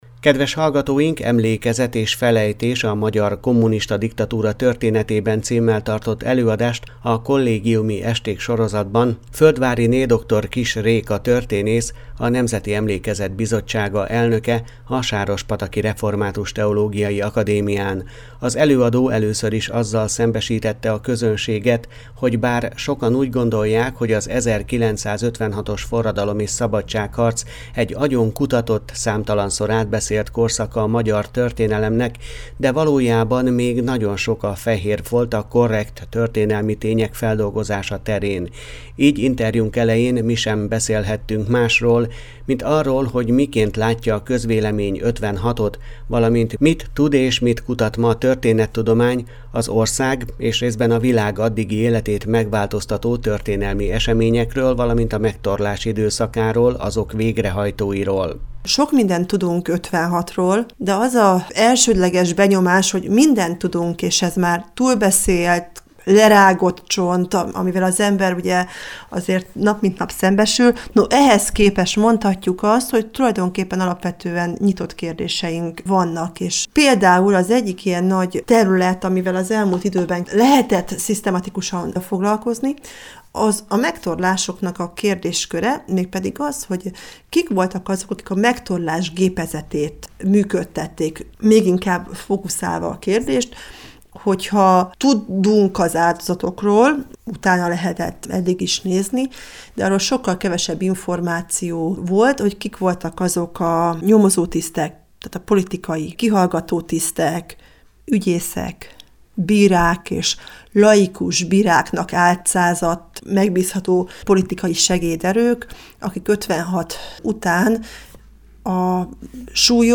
Emlékezet és felejtés a magyar kommunista diktatúra történetében címmel tartott előadást a kollégiumi esték sorozatban Földváryné dr. Kiss Réka történész, a Nemzeti Emlékezet Bizottsága elnöke a Sárospataki Református Teológiai Akadémián.